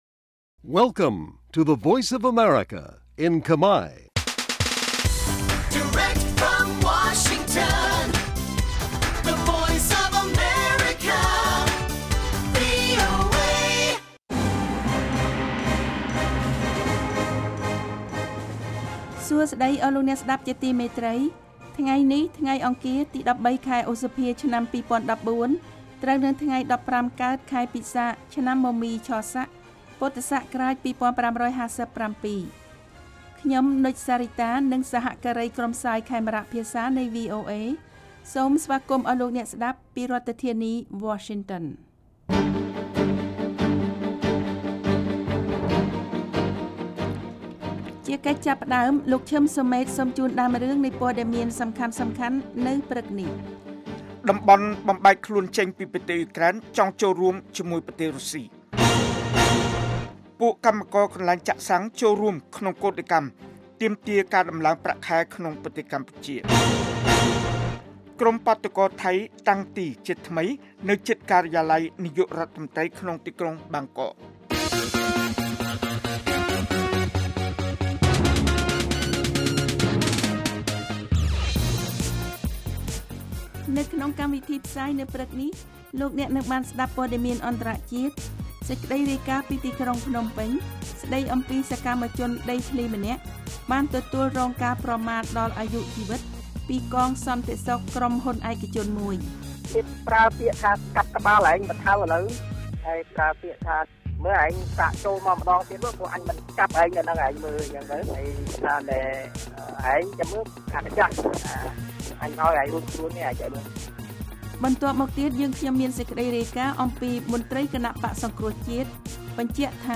នេះជាកម្មវិធីផ្សាយប្រចាំថ្ងៃតាមវិទ្យុជាភាសាខ្មែរ រយៈពេល ៣០នាទី ដែលផ្តល់ព័ត៌មានអំពីប្រទេសកម្ពុជានិងពិភពលោក ក៏ដូចជាព័ត៌មានពិពណ៌នា ព័ត៌មានអត្ថាធិប្បាយ និងបទវិចារណកថា ជូនដល់អ្នកស្តាប់ភាសាខ្មែរនៅទូទាំងប្រទេសកម្ពុជា។ កាលវិភាគ៖ ប្រចាំថ្ងៃ ម៉ោងផ្សាយនៅកម្ពុជា៖ ៥:០០ ព្រឹក ម៉ោងសកល៖ ២២:០០ រយៈពេល៖ ៣០នាទី ស្តាប់៖ សំឡេងជា MP3